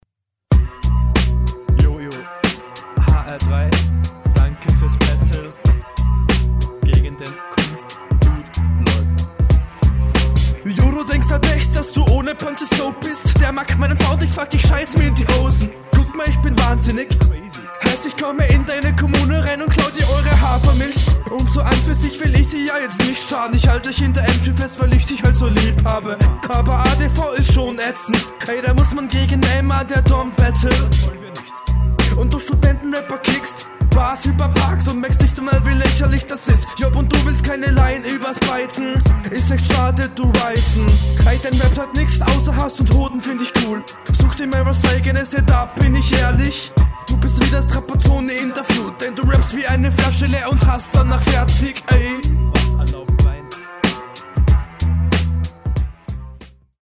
Nicer old school beat lel.